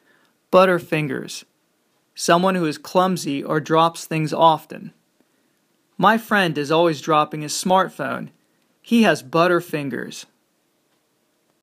マンツーマンのレッスン担当の英語ネイティブによる発音は下記のリンクをクリックしてください。